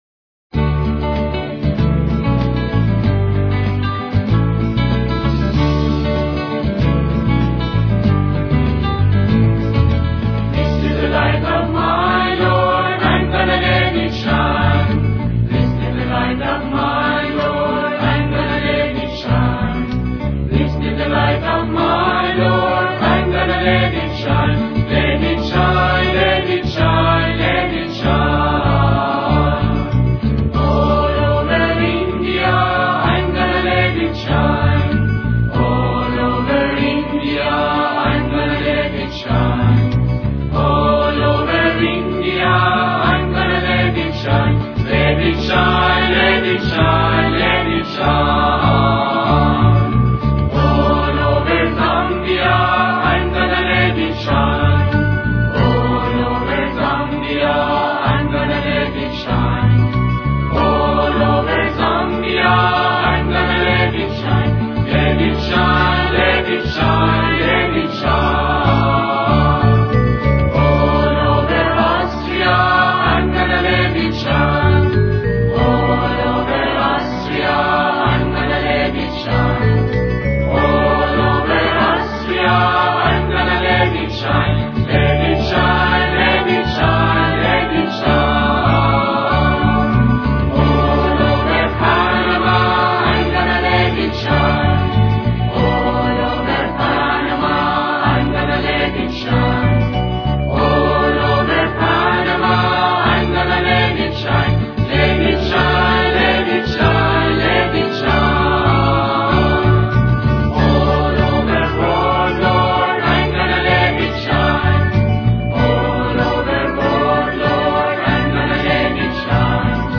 مجموعه سرود و مناجات به زبان انگلیسی